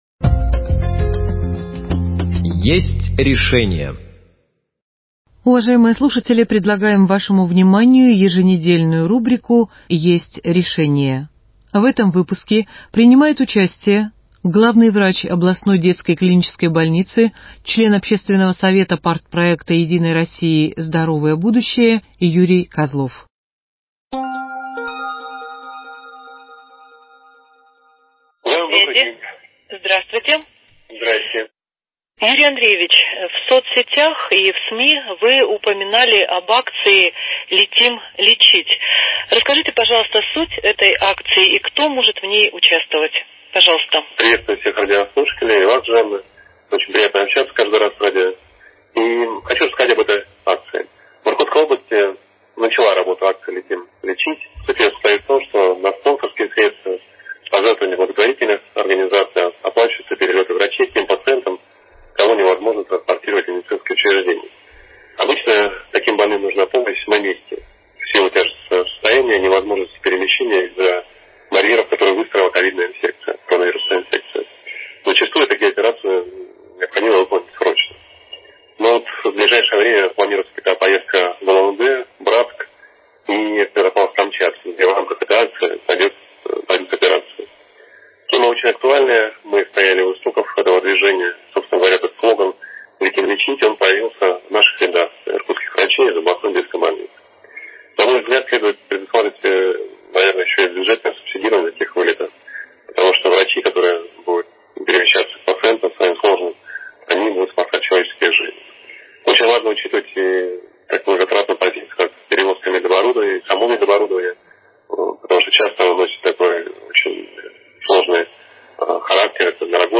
Беседует с ним по телефону